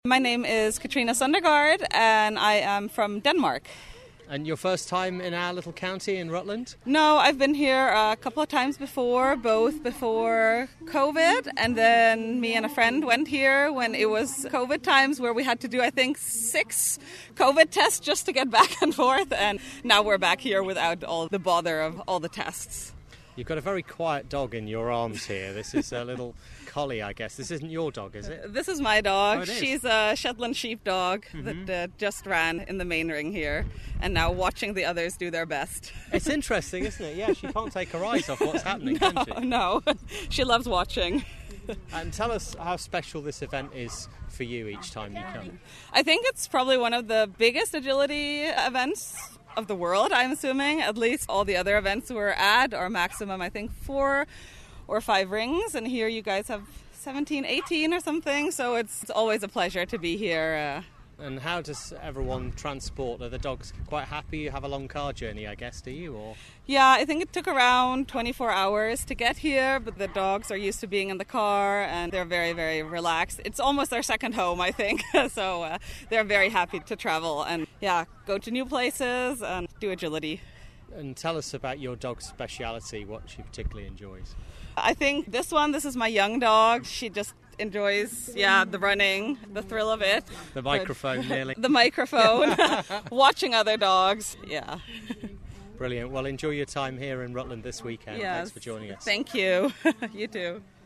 Kennel Club Agility at Rutland Showground - Denmark competitor